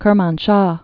(kĕr-mänshä, -shô)